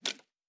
Fantasy Interface Sounds
Weapon UI 08.wav